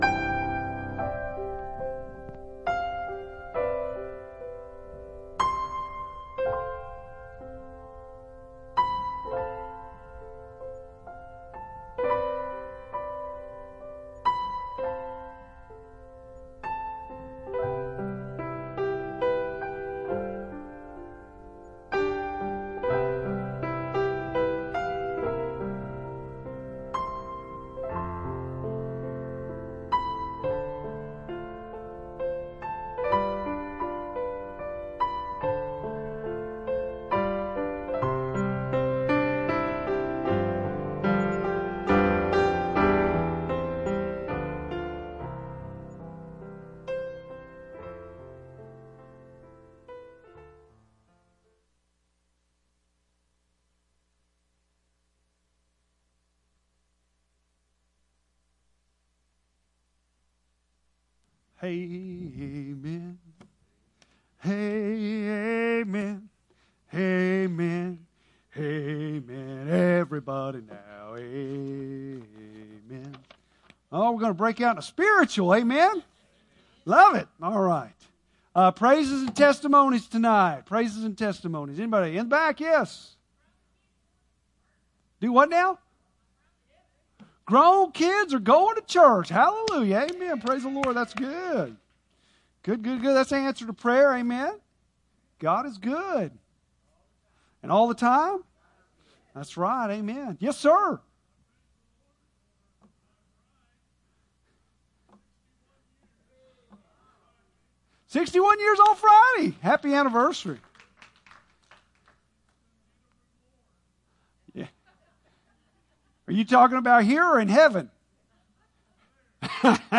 Bible Text: Revelation 9:13-21 | Preacher